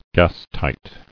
[gas·tight]